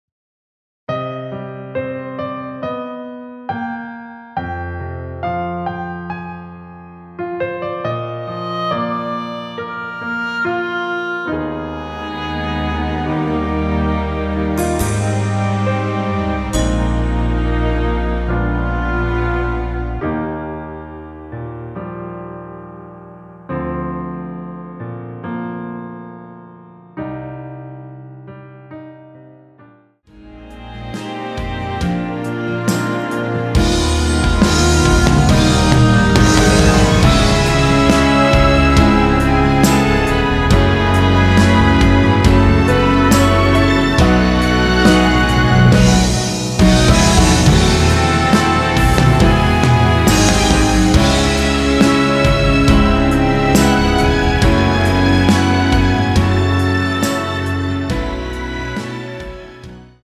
원키에서(-2)내린 MR 입니다.(미리듣기 참조)
Eb
앞부분30초, 뒷부분30초씩 편집해서 올려 드리고 있습니다.
중간에 음이 끈어지고 다시 나오는 이유는